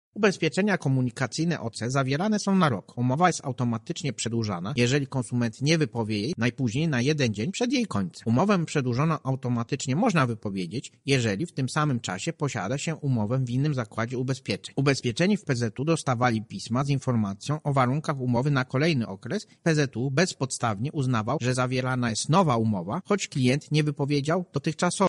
O poprawnej możliwości zawierania polisy OC mówi Marek Niechciał, prezes UOKiK.